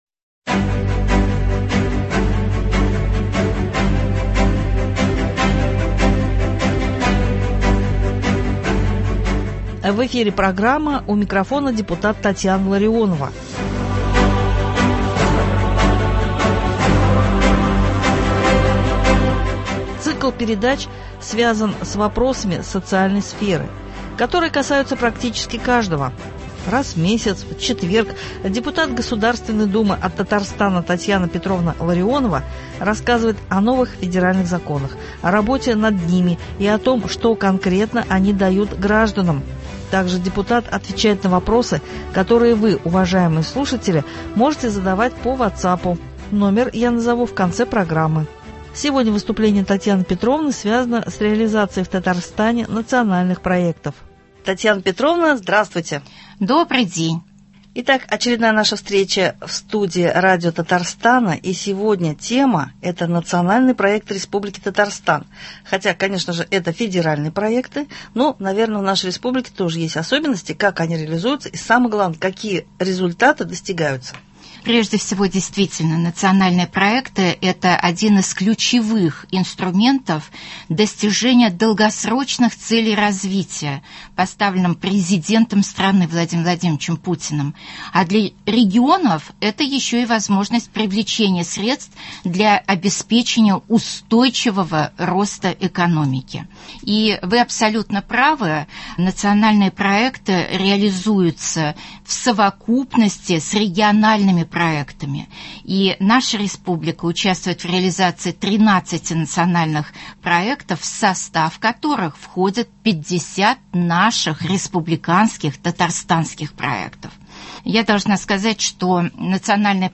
Раз в месяц в четверг депутат Государственной Думы от Татарстана Татьяна Петровна Ларионова рассказывает о новых федеральных законах, о работе над ними и о том, что конкретно они дают гражданам. Также депутат отвечает на вопросы, которые вы, уважаемые слушатели , можете задавать по вотсапу, номер я назову в конце программы. Сегодня выступление Татьяны Петровны связано с реализацией в Татарстане национальных проектов.